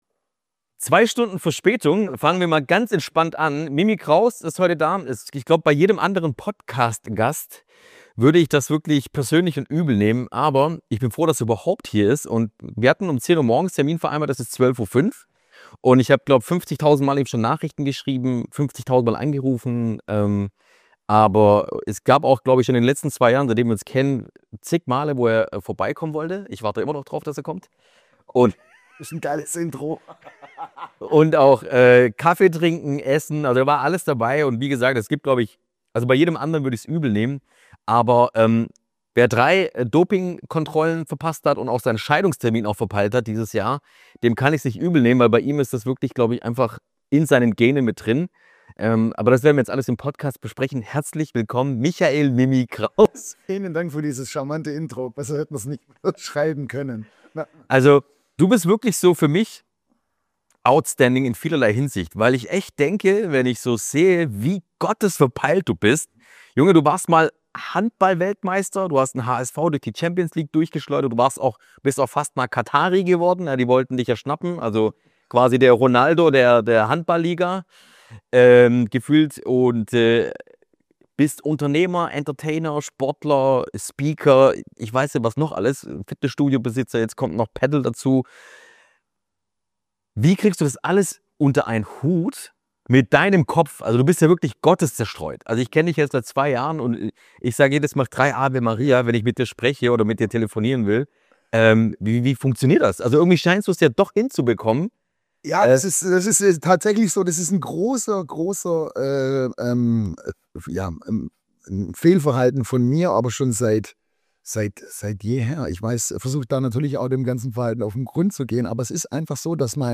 In dieser Folge spricht Mimi Kraus so offen wie nie über seinen Weg vom Spitzensportler zum Unternehmer, über Doping-Skandale, das Ende seiner Ehe, das Vatersein – und warum der Neuanfang manchmal der ehrlichere Weg ist. Ein Gespräch über Familie, Verantwortung, Disziplin, öffentliche Fehler und die Kraft, sich immer wieder neu zu erfinden.